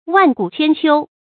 萬古千秋 注音： ㄨㄢˋ ㄍㄨˇ ㄑㄧㄢ ㄑㄧㄡ 讀音讀法： 意思解釋： 形容延續的時間極久。